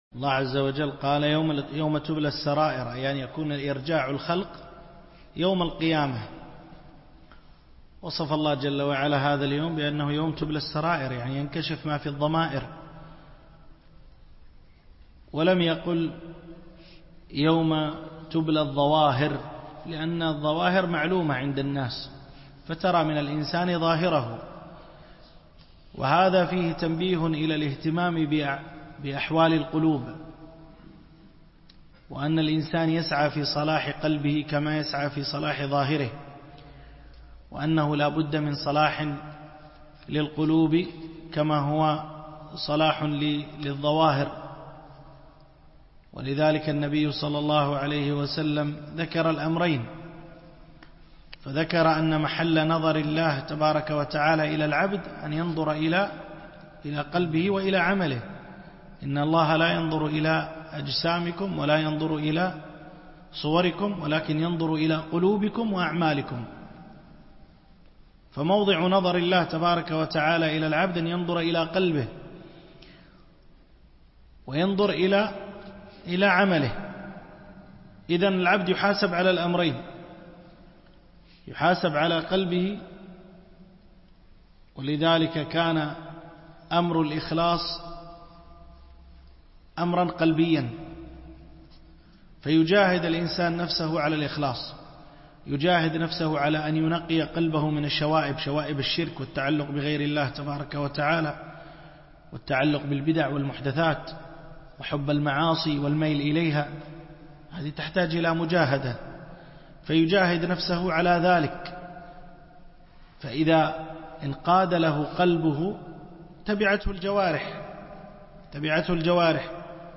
القسم: التفسير